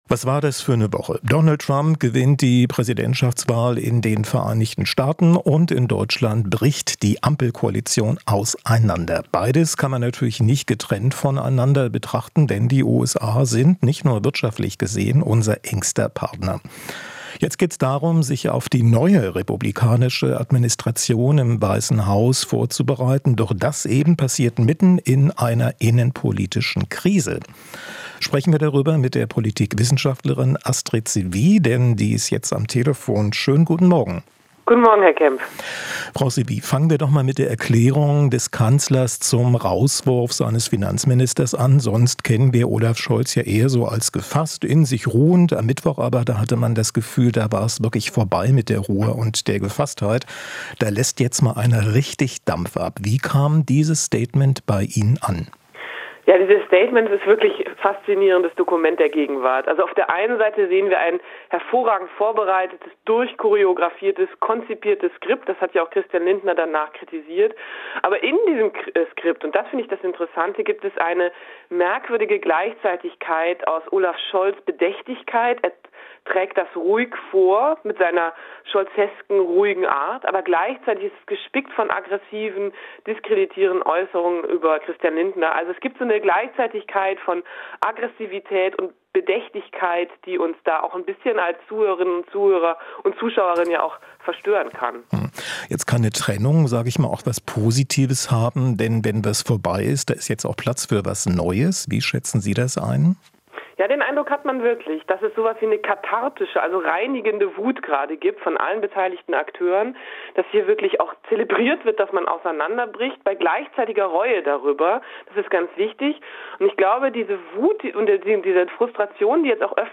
Interview - Politologin: Vom Ampel-Bruch sofort in den Wahlkampf